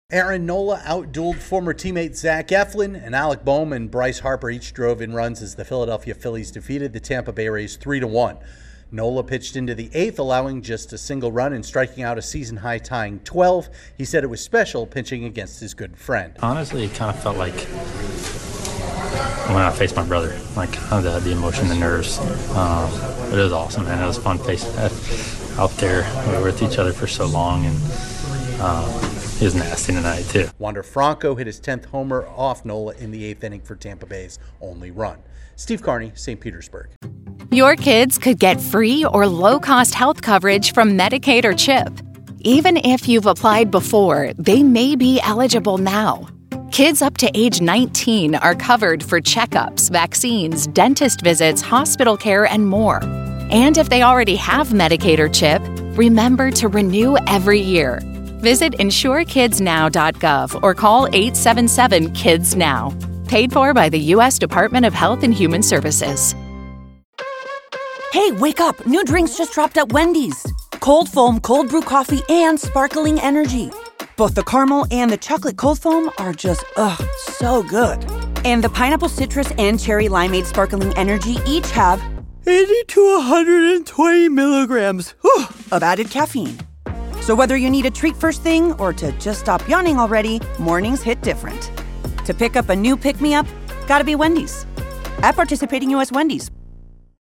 The Phillies win a mound duel of ex-teammates. Correspondent